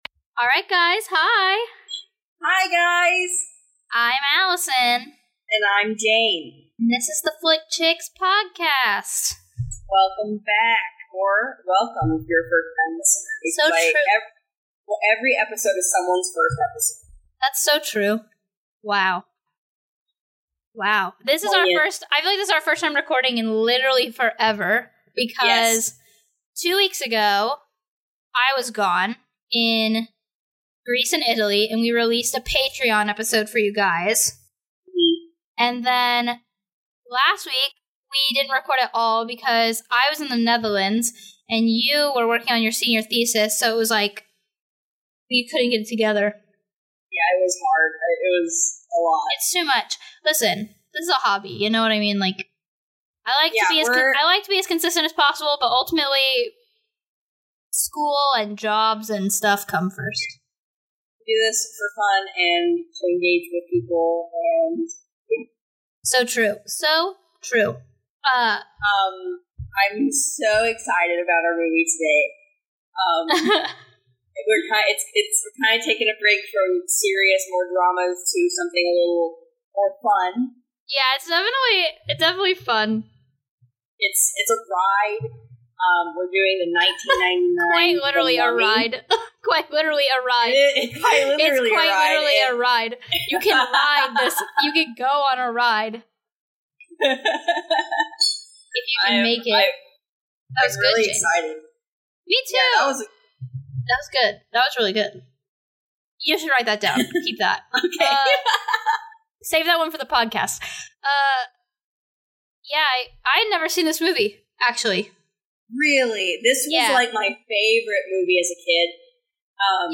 This week the girls discuss the 90s treasure, The Mummy starring Brenden Fraser.